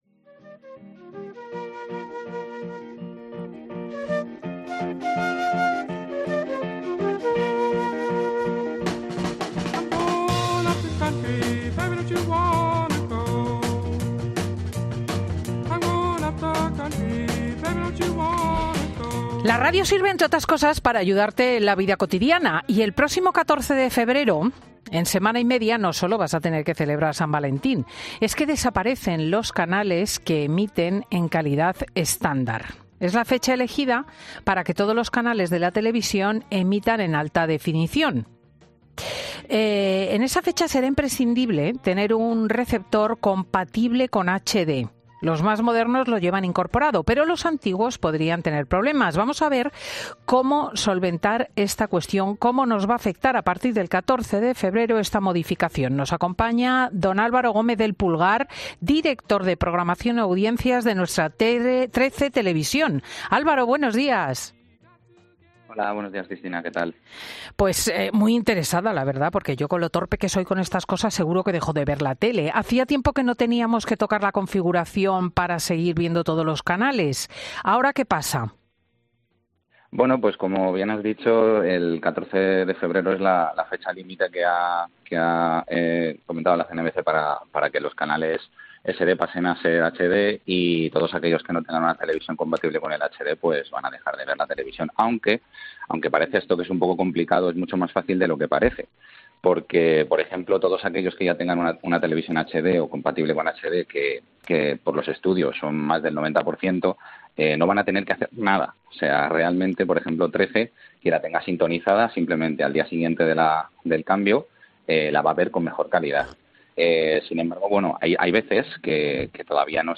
Los que ya tengan una televisión HD (que por los estudios son más del 90%) no van a tener que hacer nada" explicaba en los micrófonos de Fin de Semana.